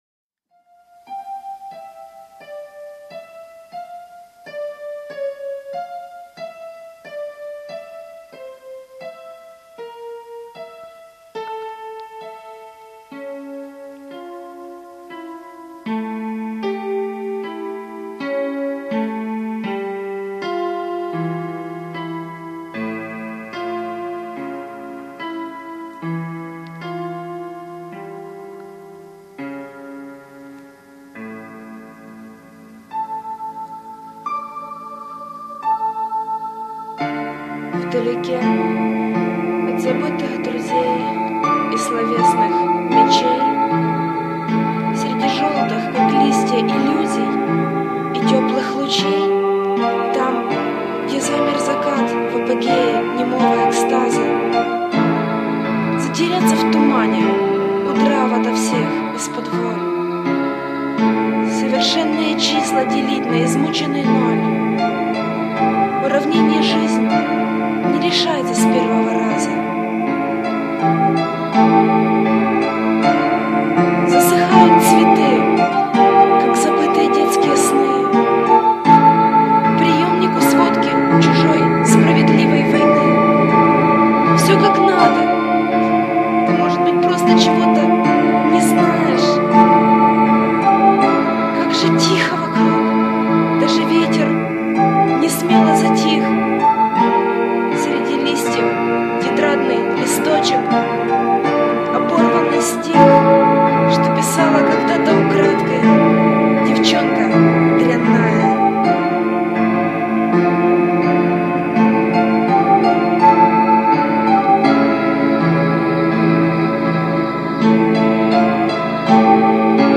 И качество не такое уж плохое!
Музыка замечательная...Но проскочила мысль что они местами не сочетаются.
Он у Вас прекрасный на самом деле) Не надо его прятать в громкости синтезатора) Вы молодчинка, правда) smile smile wink
На счет громкости голоса, то... проект, в первую очередь, все-таки музыкальный (большая часть треков инструментальные), и я не хочу акцентировать внимание только на тексте...
И написана, и сыграна... и записана тоже (уж извините за качество apple )